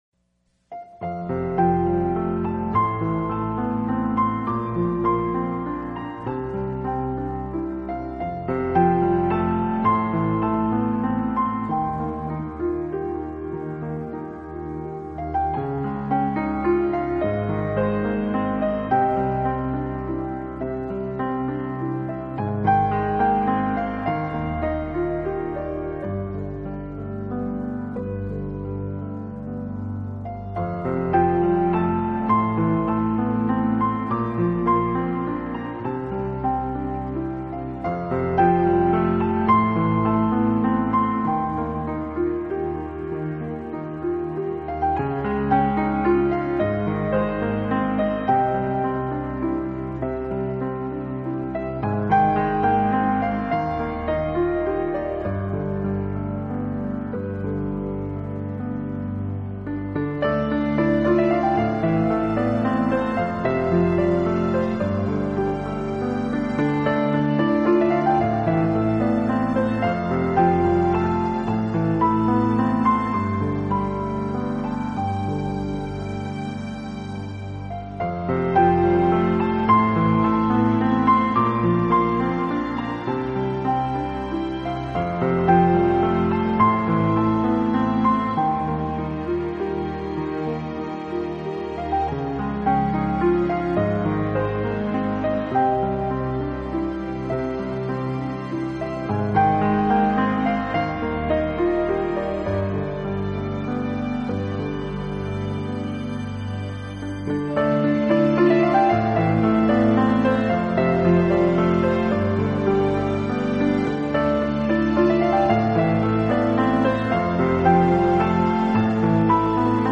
音乐类型：New Age, Piano